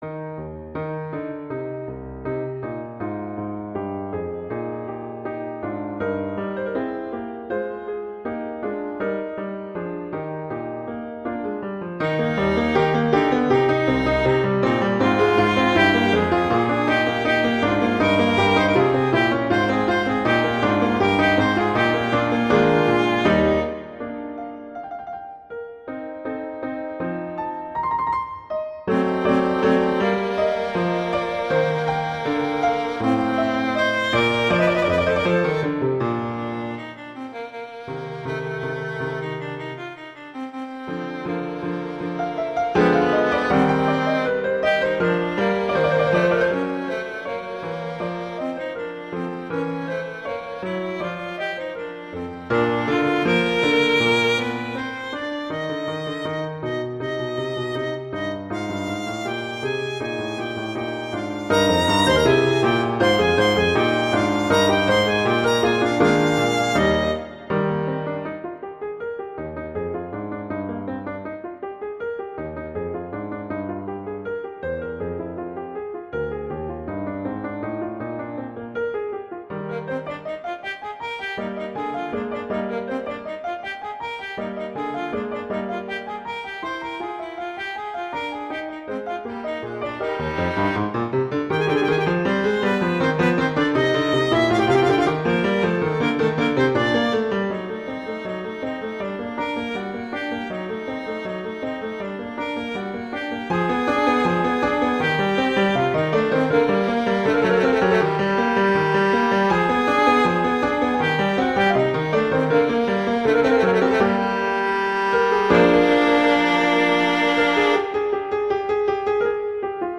violin and piano
classical
♩=80-110 BPM (real metronome 80-108 BPM)